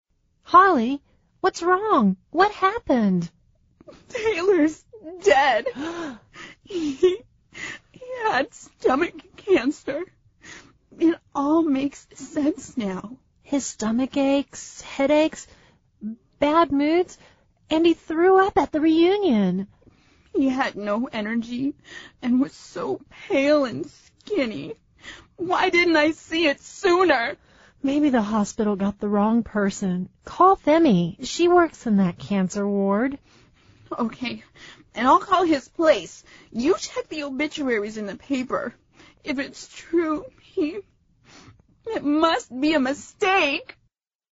美语会话实录第244期(MP3+文本):Must be a mistake!